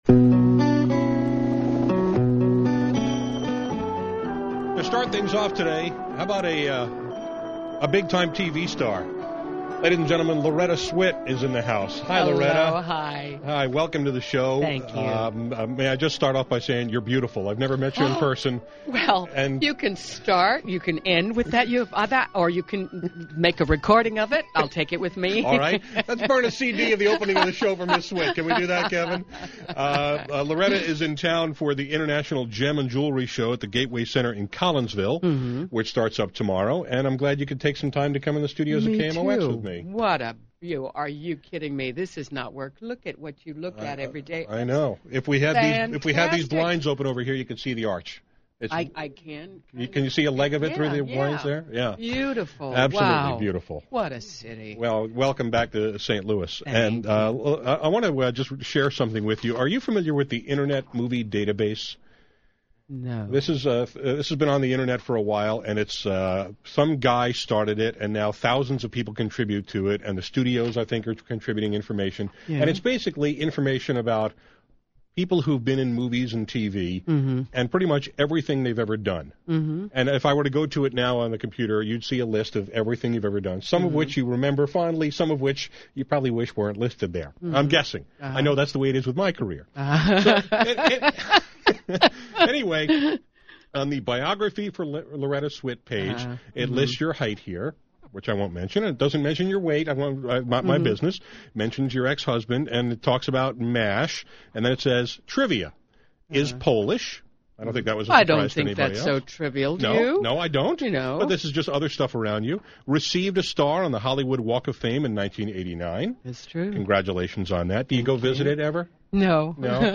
Here’s my conversation with Loretta Swit, who played Major Margaret Houlihan on TV’s “M*A*S*H.” We discussed why the plug was finally pulled after 11 seasons and why she had such passion for the Hot Lips character, what her favorite episode was and how Hot Lips evolved through the years, why she didn’t do a spinoff show, and her relationship with McLean Stevenson (Col. Henry Blake).